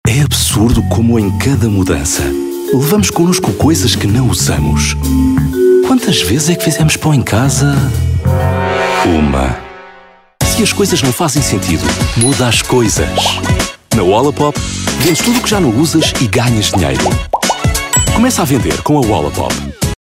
Voice Samples: TV Commercials
male